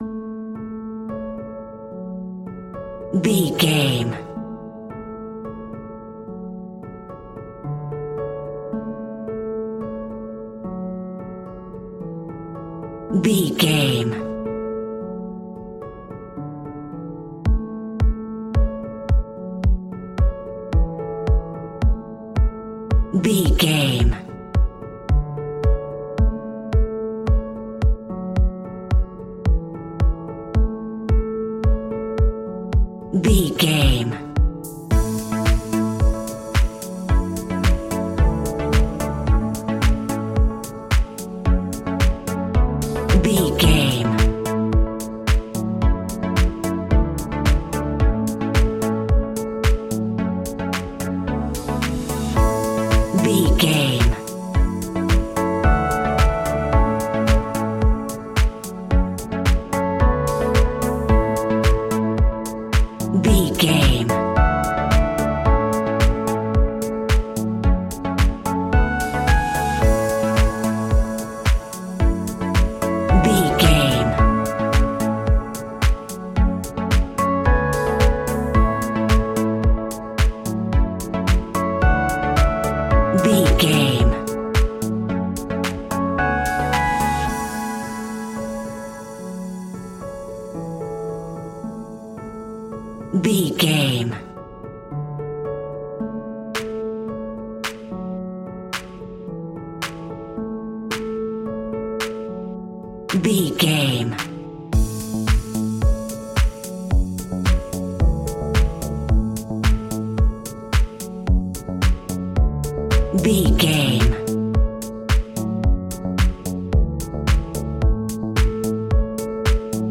Ionian/Major
groovy
uplifting
energetic
repetitive
synthesiser
drums
electric piano
electronic
techno
trance
drum machine
synth leads
synth bass